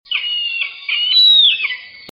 Cernícalo Americano (Falco sparverius)